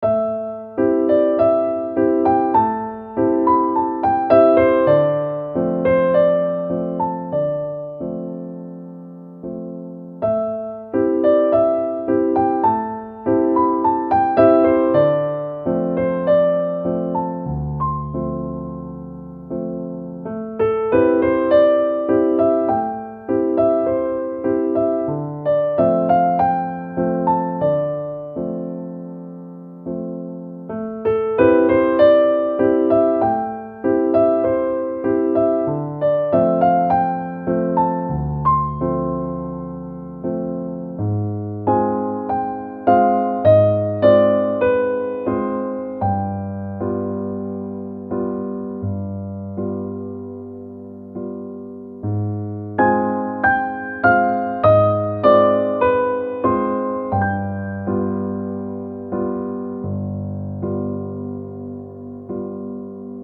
-oggをループ化-   しっとり 綺麗 2:08 mp3